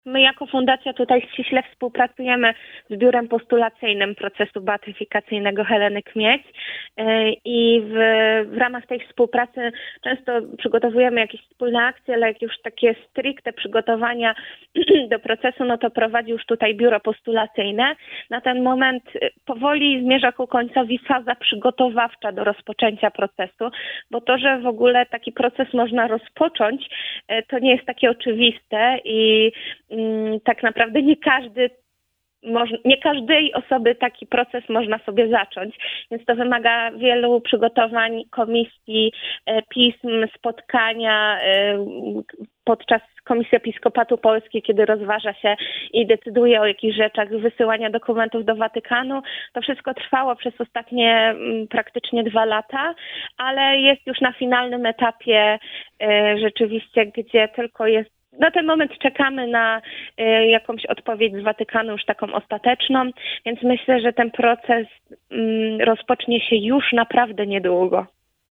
Łączymy się telefonicznie